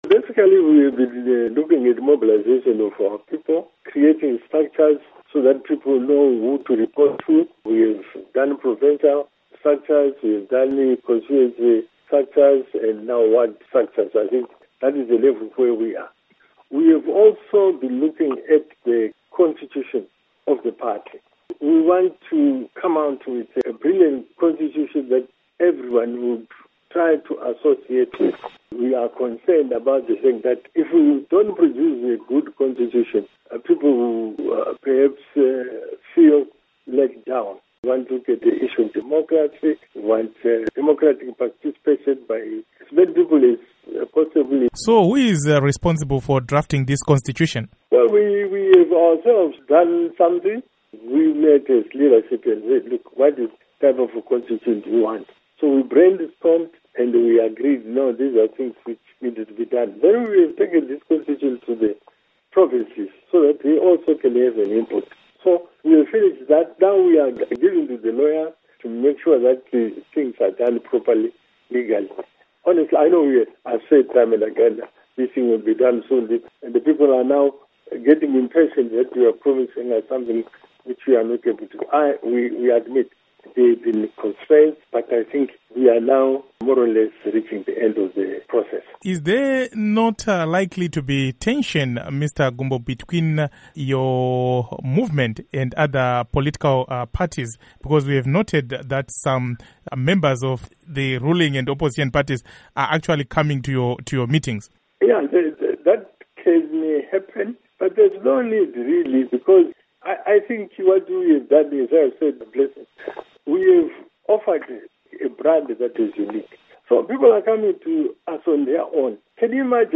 Interview With Rugare Gumbo, Defacto Spokesperson, People First